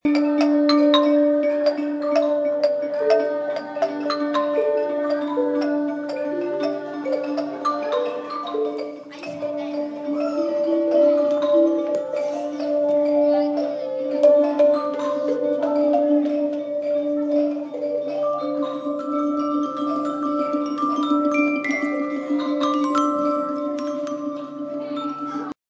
100 students improvising with the ostinato from Daedalus & Icarus